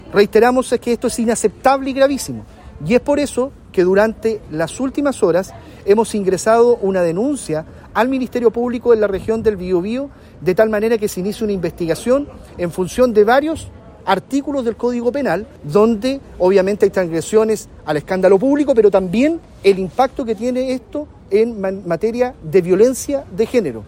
Desde el Gobierno, en voz del delegado Presidencial, Eduardo Pacheco, detallaron la denuncia presentada ante la Fiscalía y pidieron la más amplia condena a este tipo de acciones.